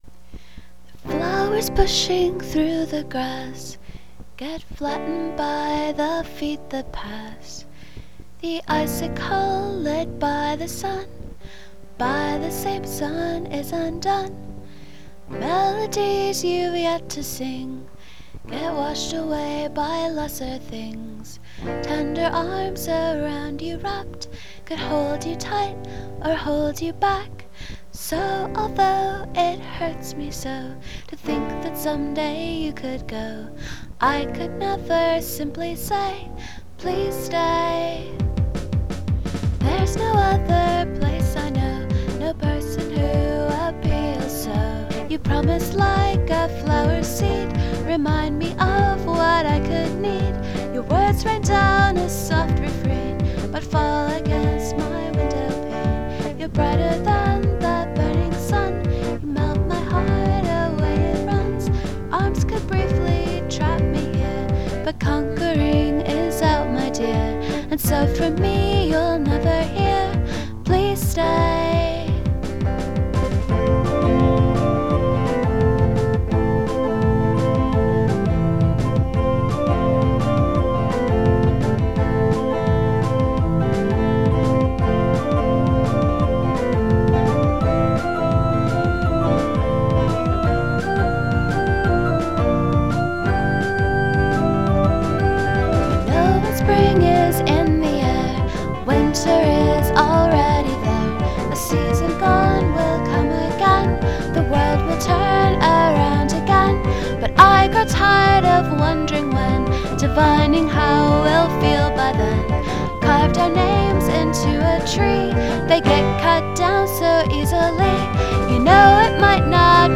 C major, D major, Bb major 7, G major x 2
a-a-b-a-c
i scrapped the middle section and wrote a shorter, vocal-free bridge that just basically gives you a break from the verses. i also reworked it for guitar so it is (theoretically) less boring. i have not recorded the new version yet.
new version is online now. i recorded this after the company holiday party. will probably redo it again at some point with sober guitars. merry christmas!